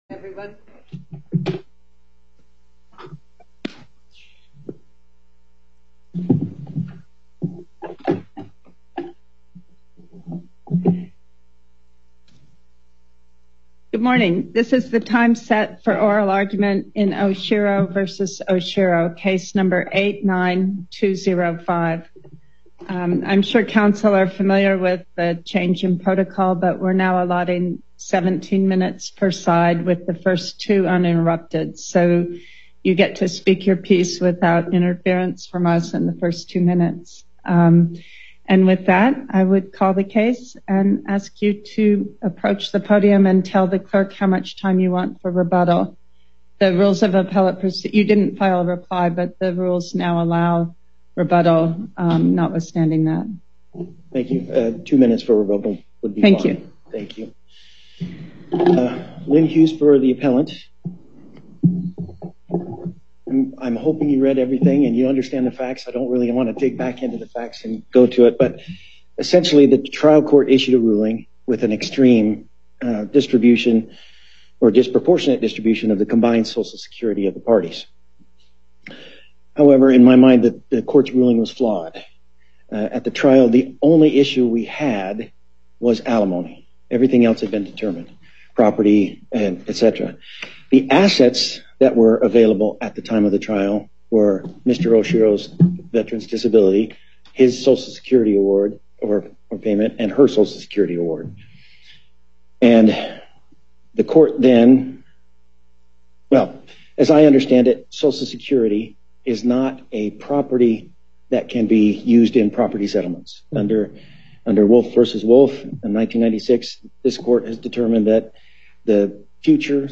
Before Panel B25, Justice Pickering presiding Appearances